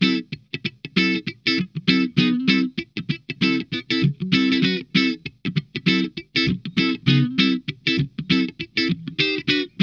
DEEP CHUG 1.wav